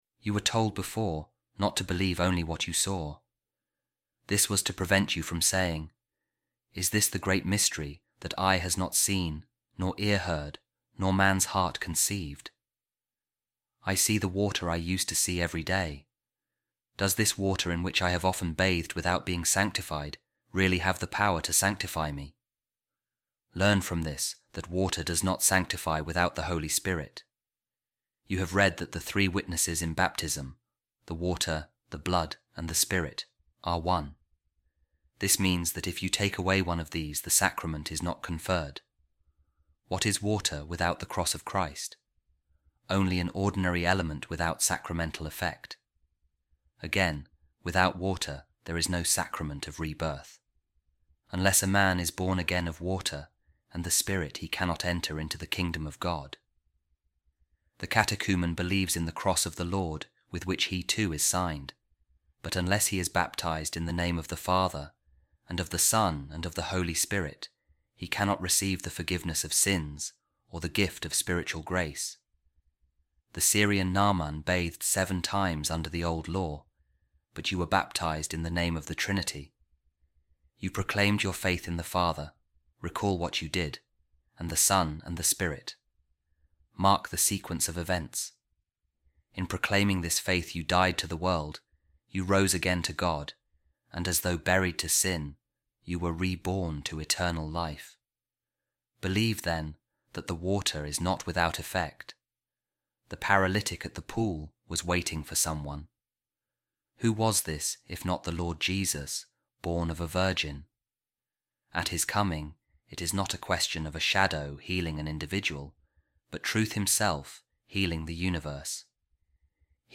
Office Of Readings | Week 15, Wednesday, Ordinary Time | A Reading From The Treatise Of Saint Ambrose On The Mysteries | Water Does Not Sanctify Without The Holy Spirit